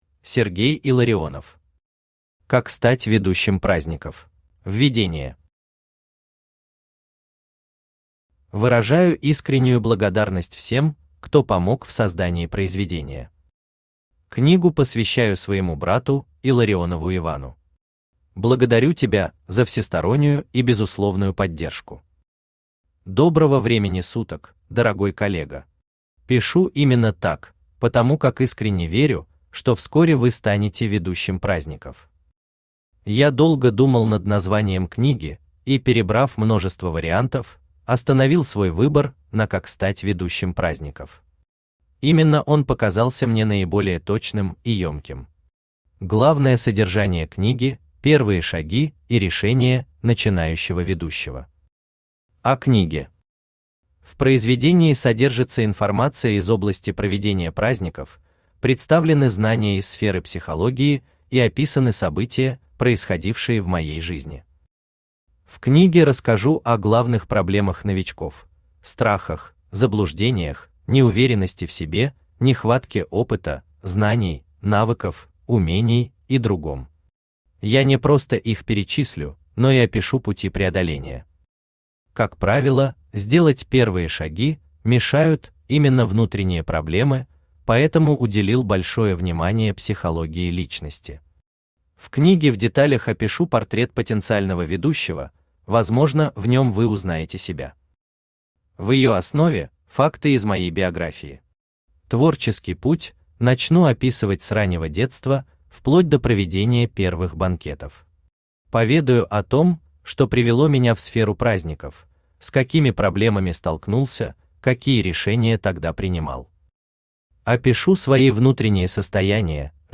Аудиокнига Как стать ведущим праздников | Библиотека аудиокниг
Aудиокнига Как стать ведущим праздников Автор Сергей Иванович Илларионов Читает аудиокнигу Авточтец ЛитРес.